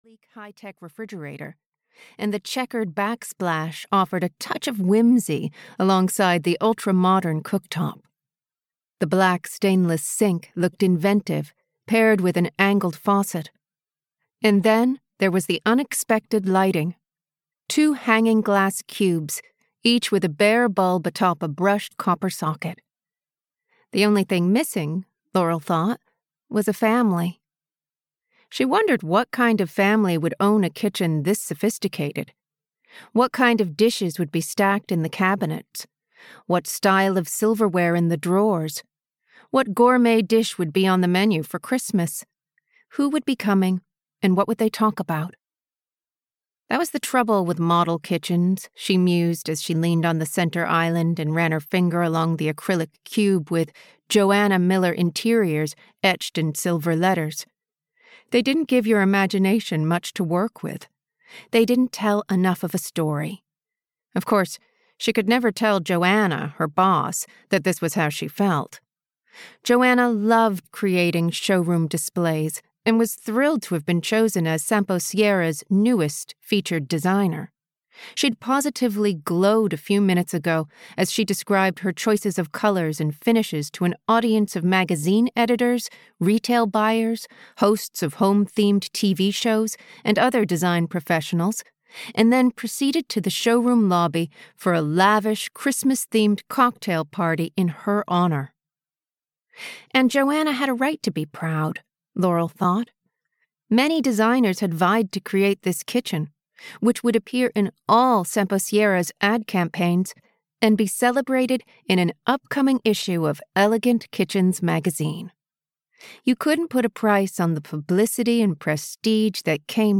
The Cranberry Inn (EN) audiokniha
Ukázka z knihy